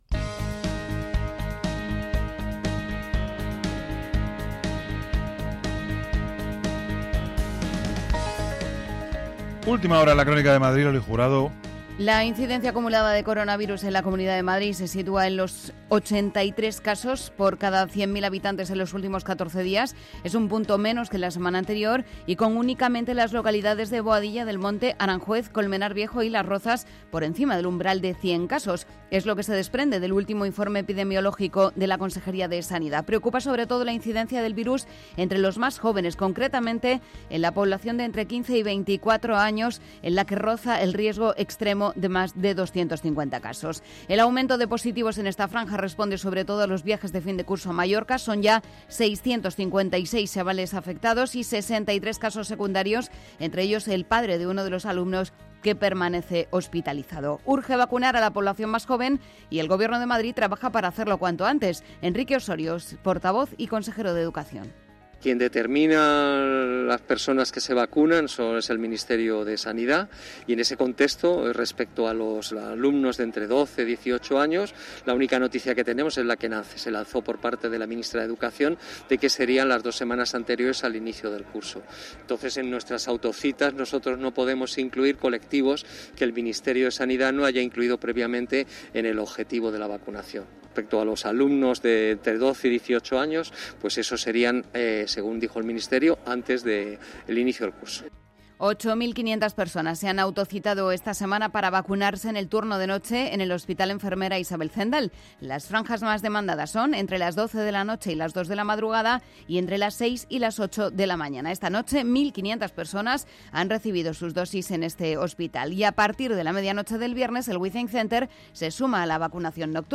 Actualidad, opinión, análisis, información de servicio público, conexiones en directo, entrevistas… Todo lo que necesitas para comenzar el día, desde el rigor y la pluralidad informativa.